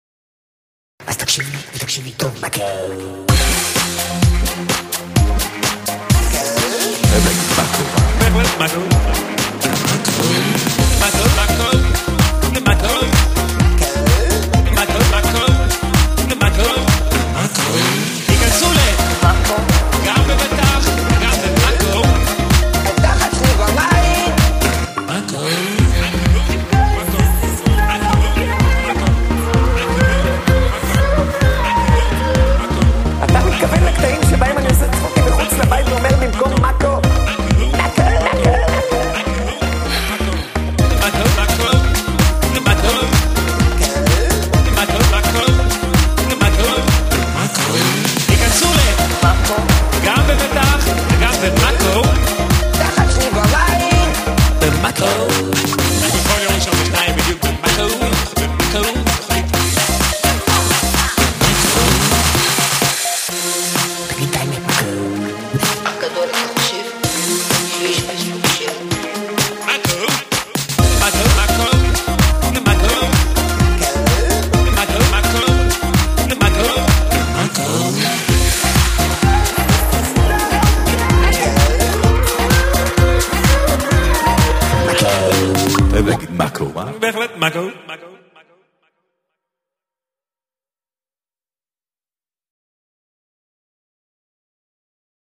גירסת המיקס